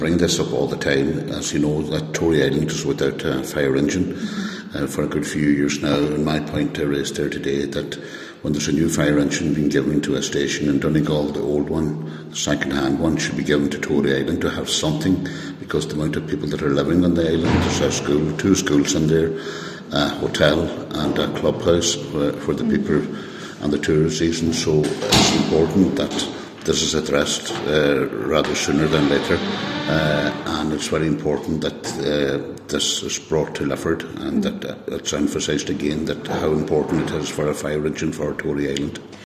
Cllr John Sheamais O’Fearraigh says when Donegal County Council updates a fire engine elsewhere in the county, the pre-existing engine should be assigned to the island.